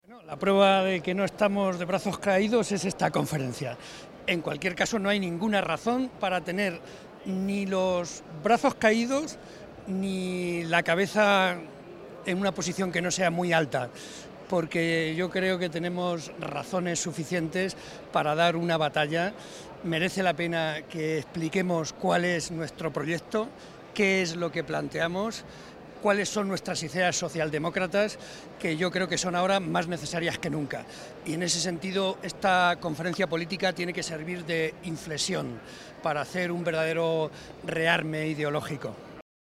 José María Barreda en la Conferencia Política del PSOE
Cortes de audio de la rueda de prensa